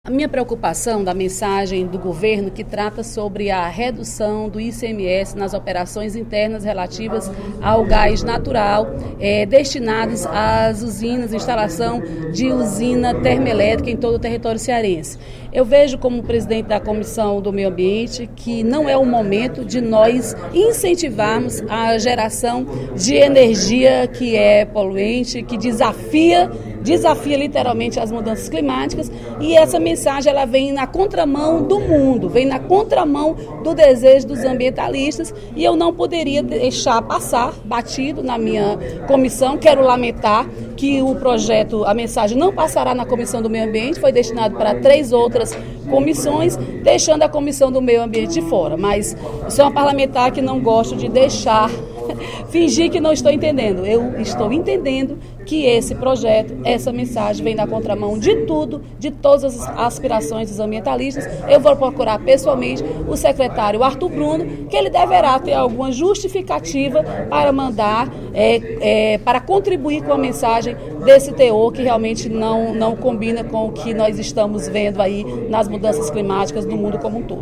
A deputada Dra. Silvana (PMDB) manifestou, no primeiro expediente da sessão plenária da Assembleia Legislativa desta quinta-feira (18/02), posição contrária à mensagem do Poder Executivo que trata da redução de ICMS do gás natural destinado às usinas termelétricas no Ceará.